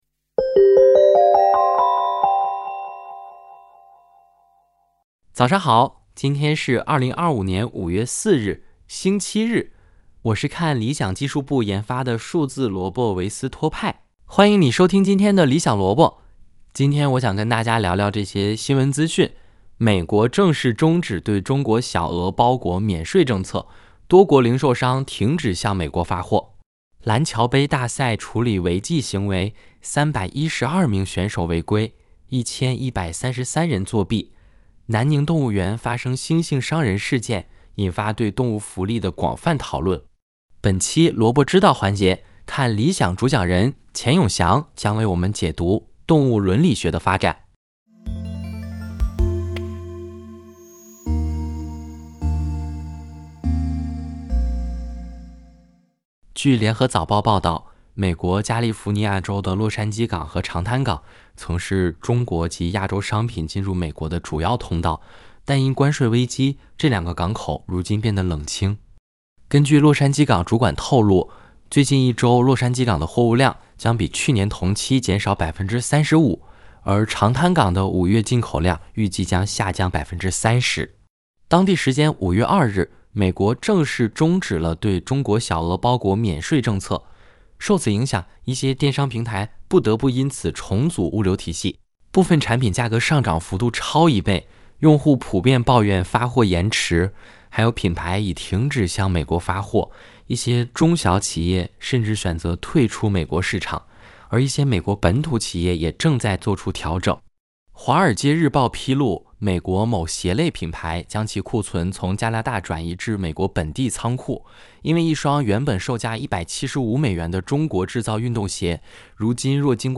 《理想萝卜》是由看理想技术部研发的数字萝卜维斯托派主持的资讯节目。